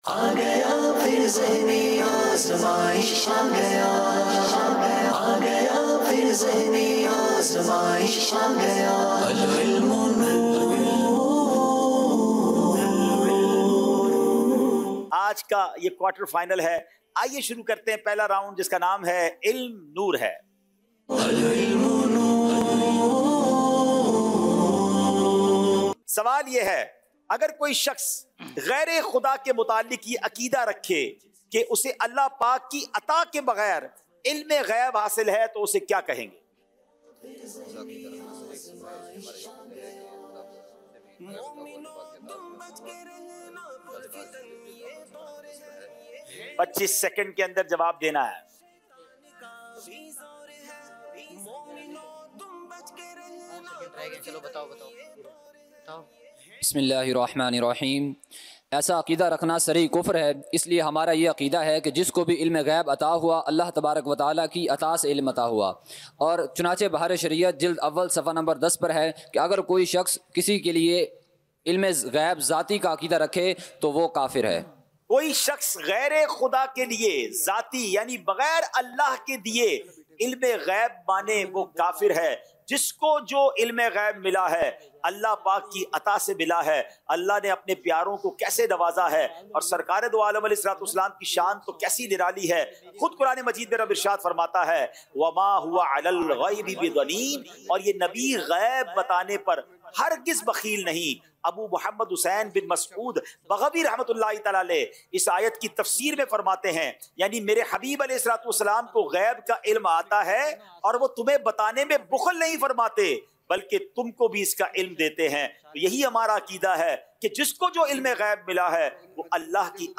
Question & Answer Session
Municipal Library Ground Kabir Wala | Zehni Azmaish Season 17